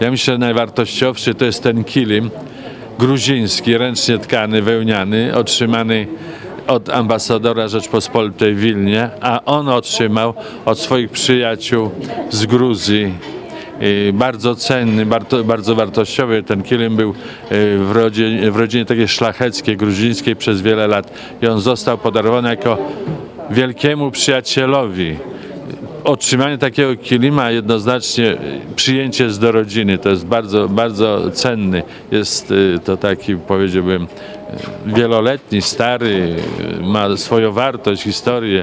W zebraniu pieniędzy pomogły licytacje, podczas których można było nabyć między innymi obrazy, koszulki piłkarzy z autografami, szablę amerykańską czy gruziński kilim. Mówi Tadeusz Chołko, wójt gminy Suwałki.
Tadeusz-Chołko-wójt-gminy-Suwałki-na-temat-balu-charytatywnego.mp3